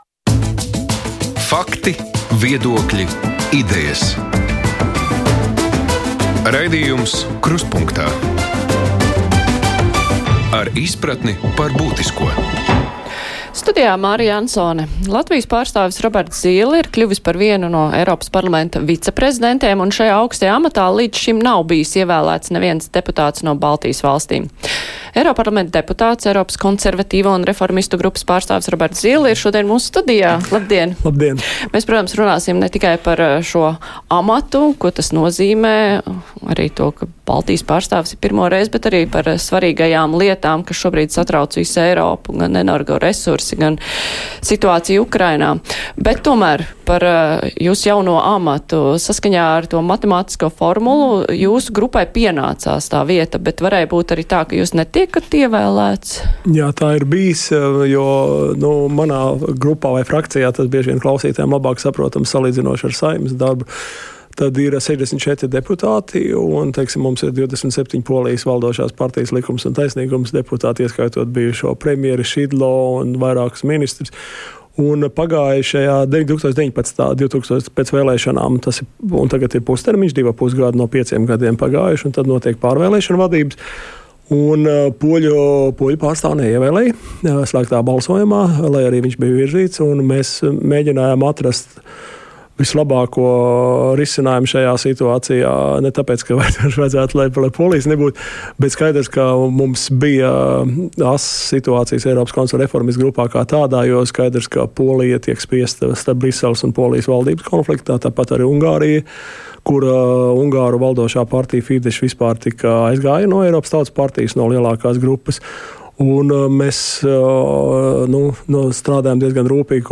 Krustpunktā Lielā intervija: Eiropas Parlamenta viceprezidents Roberts Zīle
Eiroparlamenta deputāts, Eiropas Konservatīvo un reformistu grupas pārstāvis Roberts Zīle ir Krustpunktā Lielās intervijas viesis.